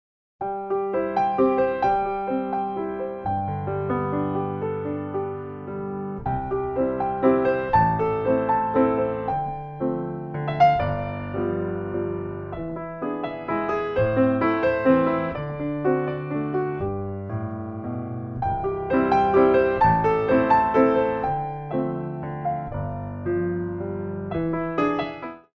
Slow Pirouette